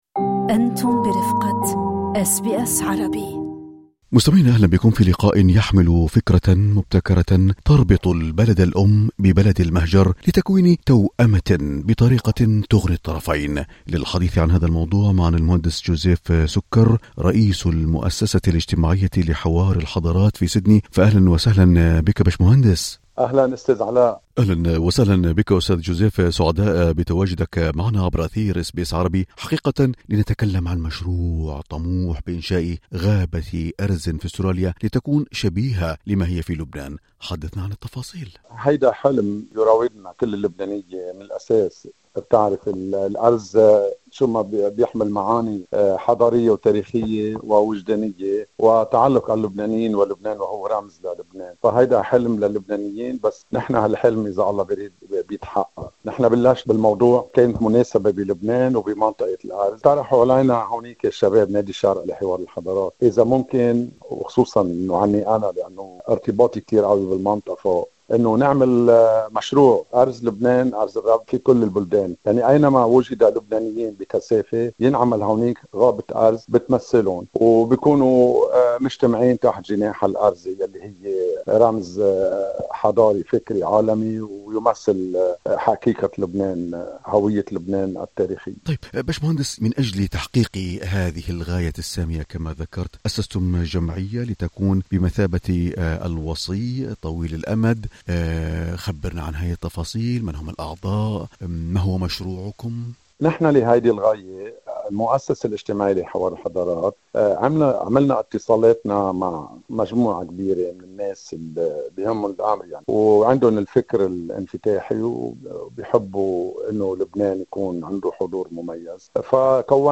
للاستماع لتفاصيل اللقاء، اضغطوا على زر الصوت في الأعلى.